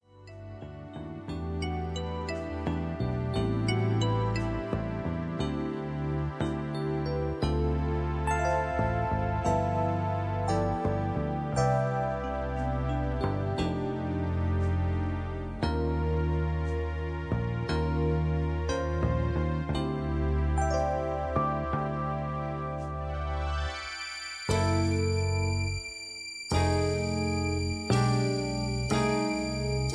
(Key-C) Karaoke MP3 Backing Tracks